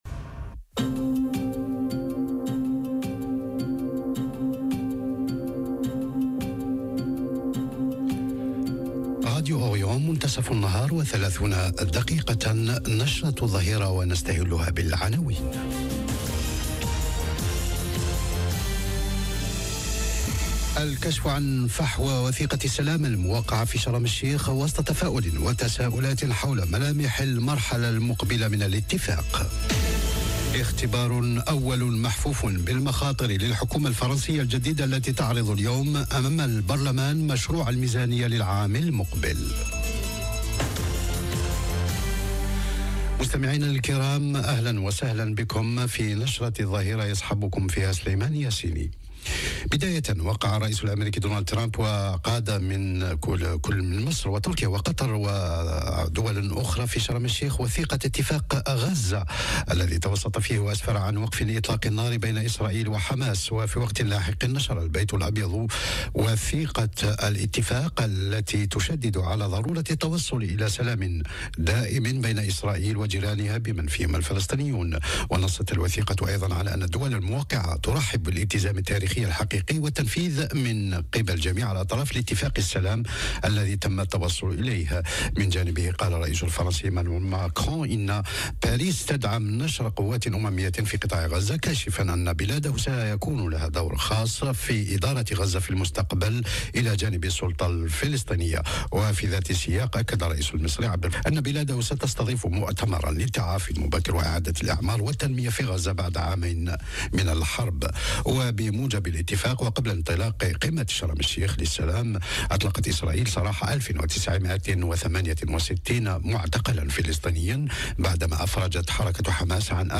نشرة أخبار الظهيرة: الكشف عن مفحوى وثيقة السلام في شرم الشيخ، والحكومة الفرنسية امام اختبار مخفوف بالمخاطر - Radio ORIENT، إذاعة الشرق من باريس